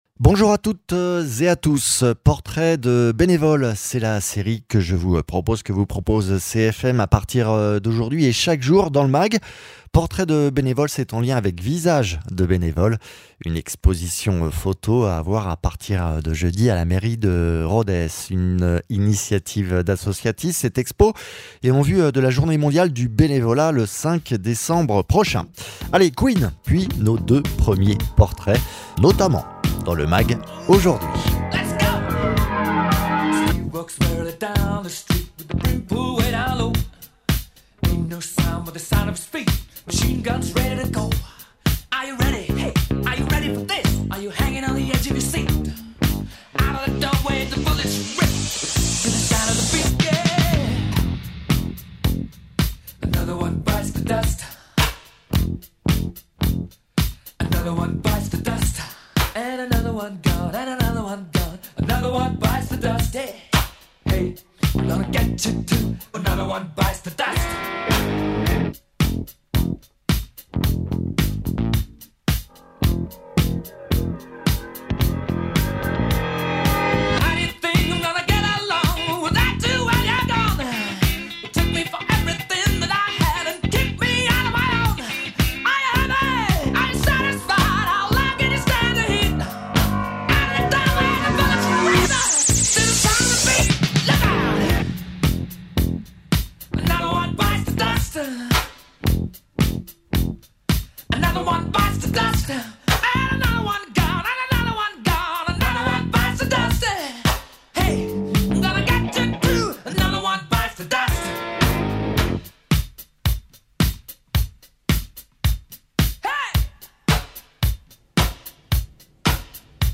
Mags
Frédéric Rubio, adjoint à la ville de Rodez en charge de la maison des associations et des festivités